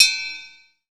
Index of /90_sSampleCDs/300 Drum Machines/Akai MPC-500/3. Perc/ChinesePrc